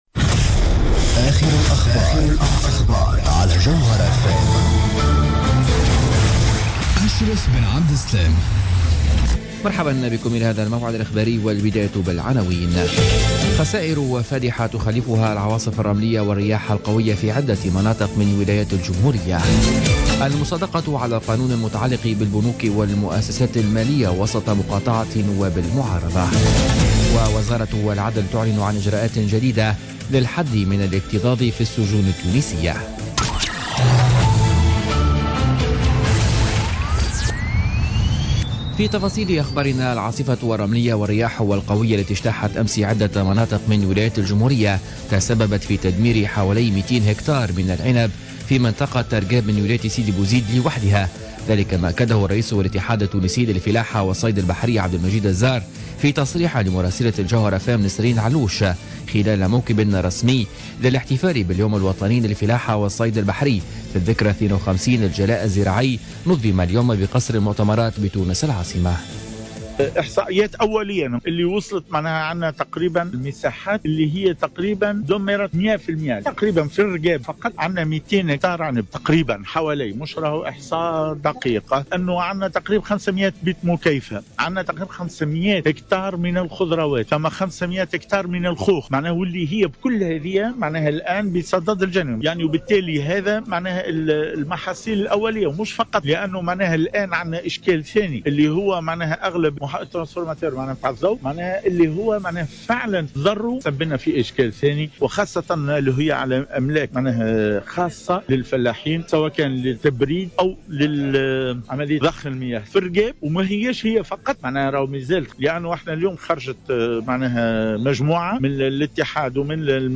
نشرة أخبار السابعة مساء ليوم الخميس 12 ماي 2016